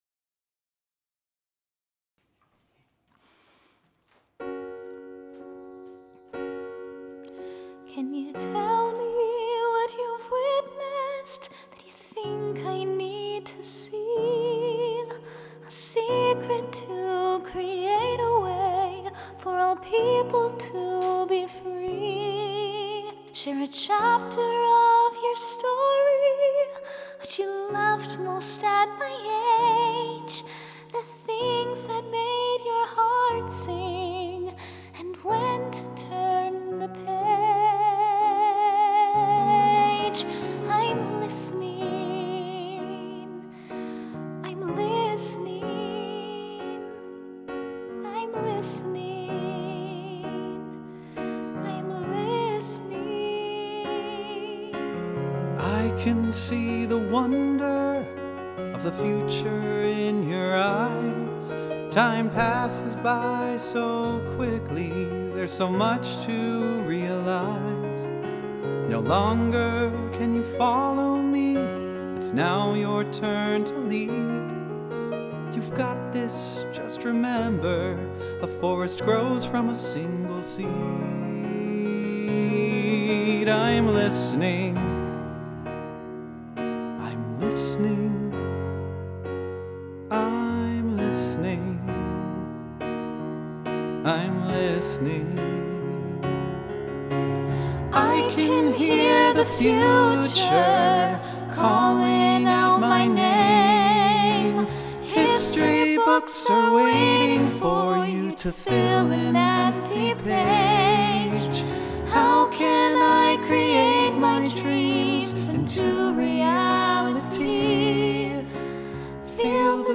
This original theme song anchors The Listening Project, a meaningful exchange where youth and elders share wisdom, stories, and enthusiasm across generations.